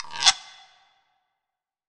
WLGUIRO.wav